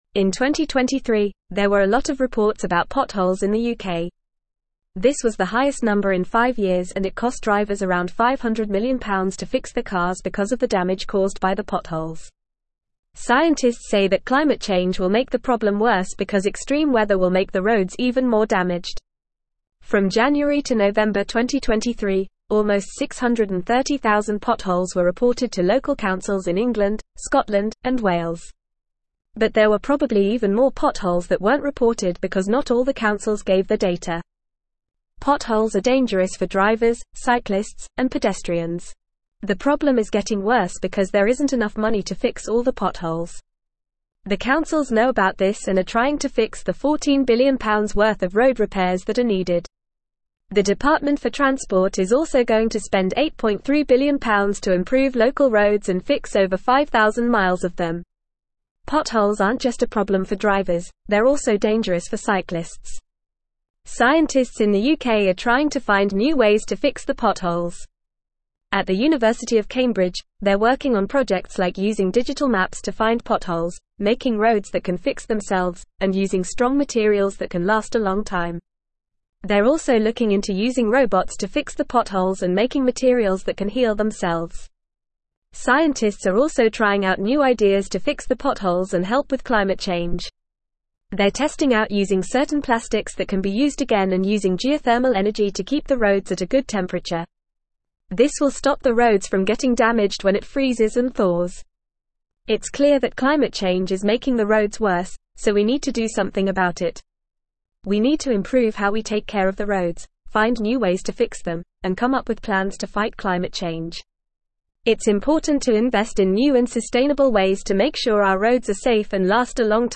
Fast
English-Newsroom-Upper-Intermediate-FAST-Reading-UK-Pothole-Problem-Climate-Change-and-Innovative-Solutions.mp3